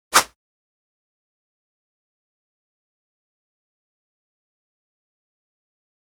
85 Swish #26, Single.wav